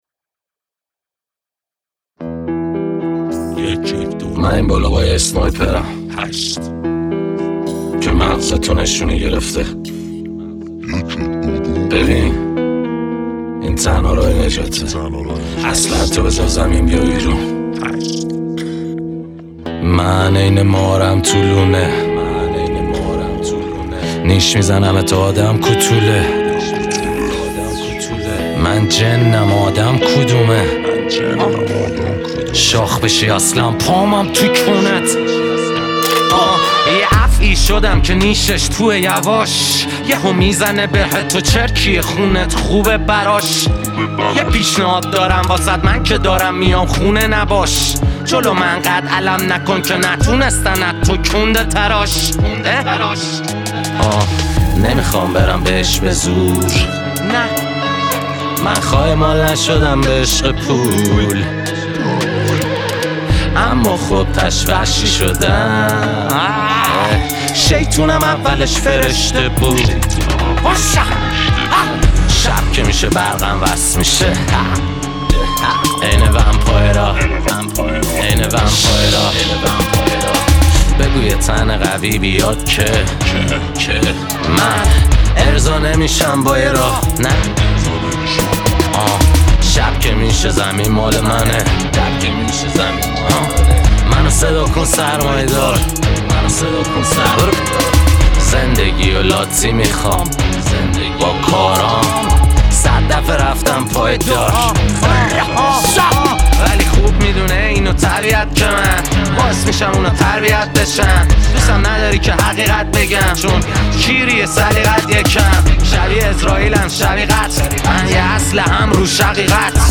تک آهنگ
آراَندبی